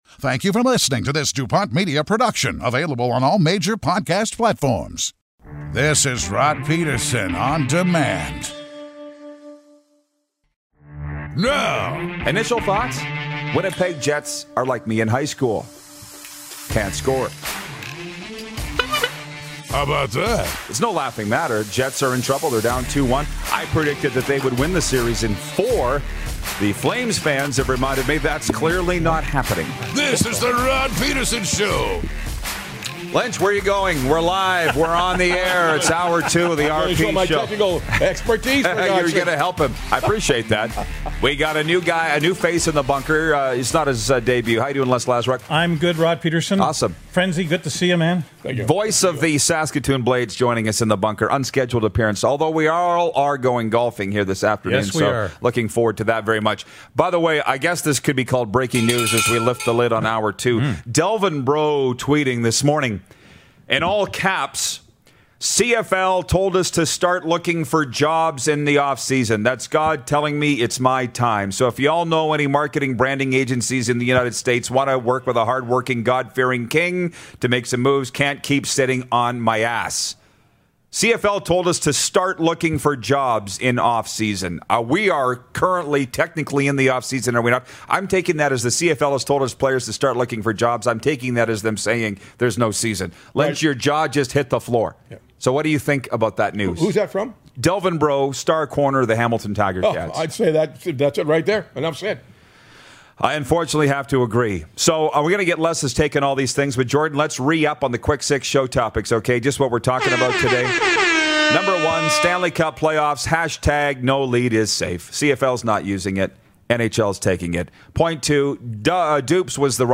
calls in to kickoff Hour 2!